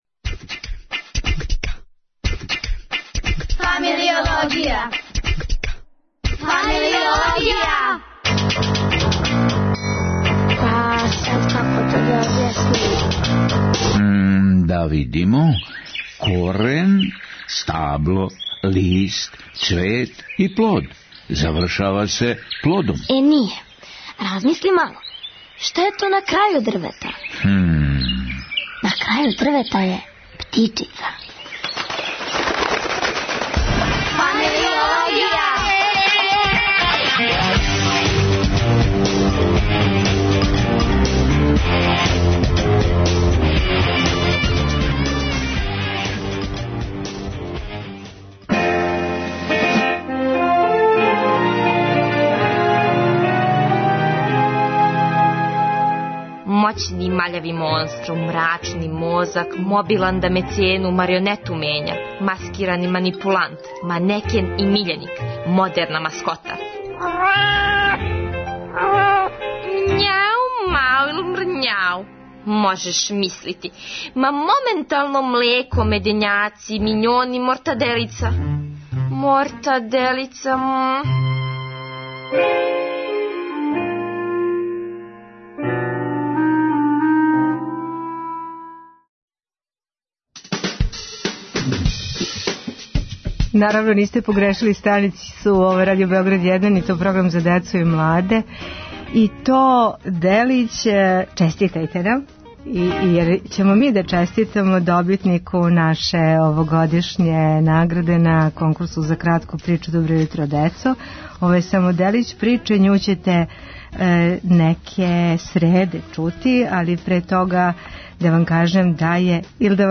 Породична прича је измишљени дијалог: Ксеније Атанасијевић и Милеве Марић Ајнштај...